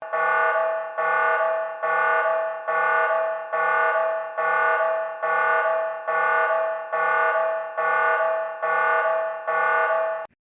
Nuclear Plant Meltdown
yt_yb13bY5-hiY_nuclear_plant_meltdown.mp3